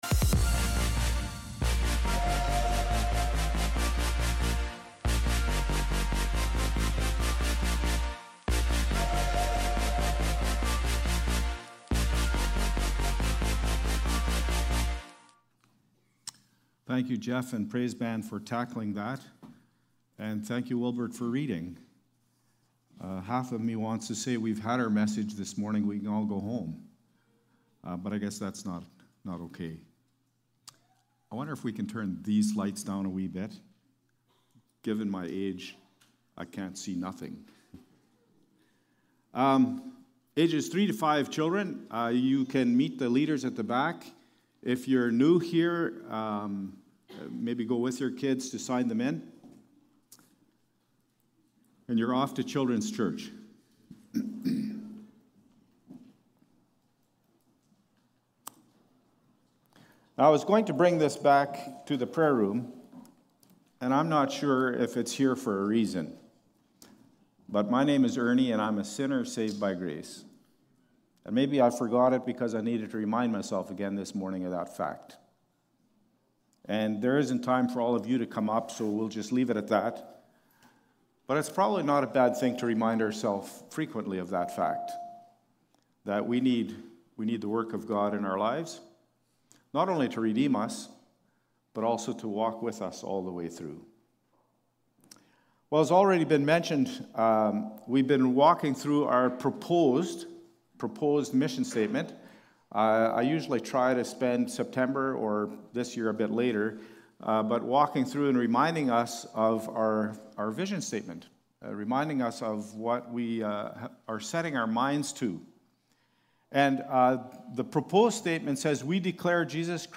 Past Message